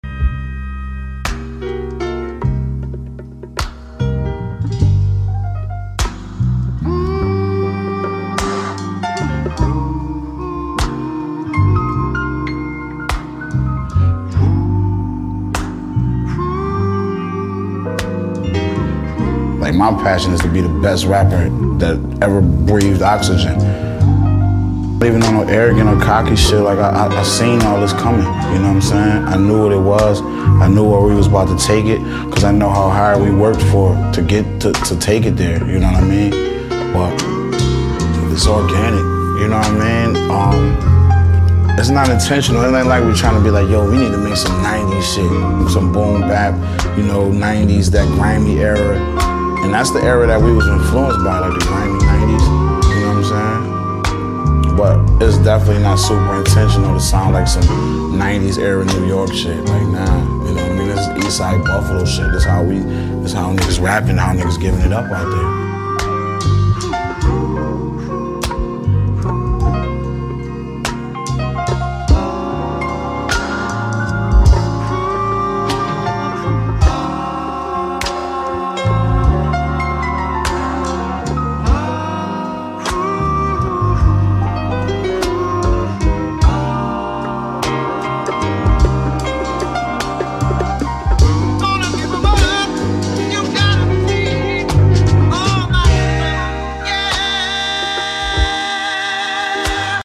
smooth vocals
rich production